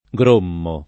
grommare v.; grommo [ g r 1 mmo ]